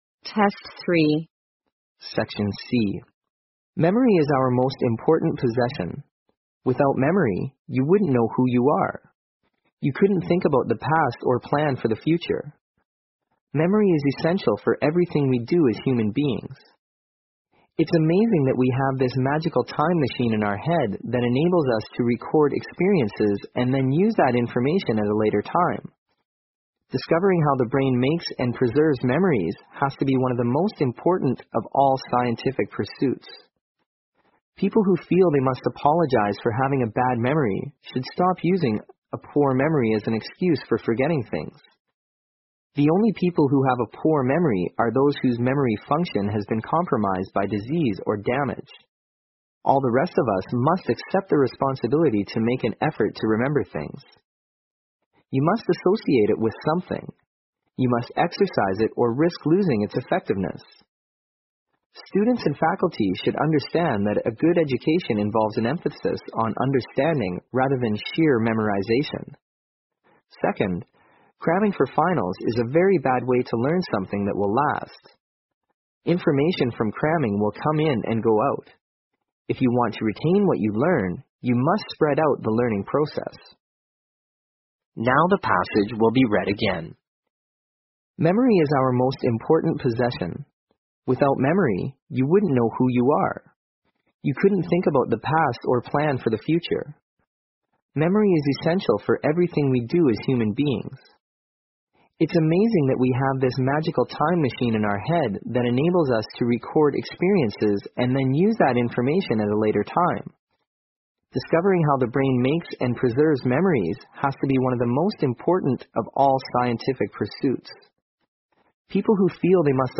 在线英语听力室226的听力文件下载,英语四级听力-短对话-在线英语听力室